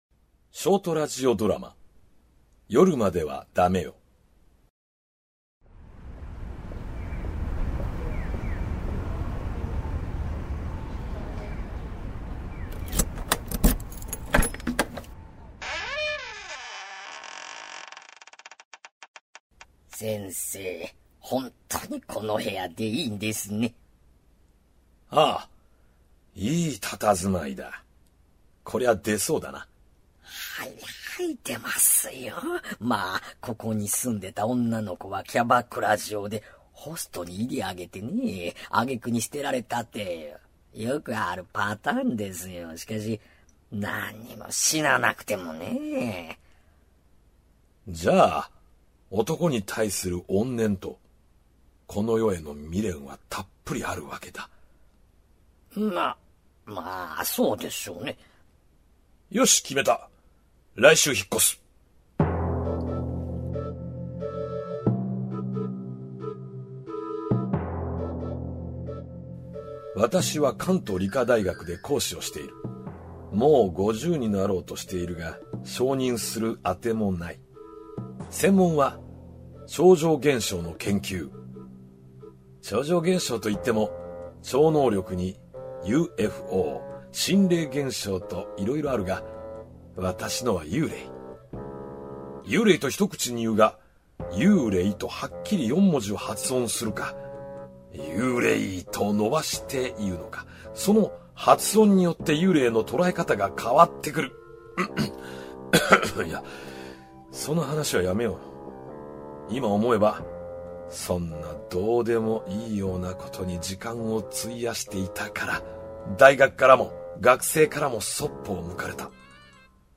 下記、音声プレイヤーを再生すると、ショートラジオドラマ「夜まではダメよ」の第一話をお聴きいただくことができます。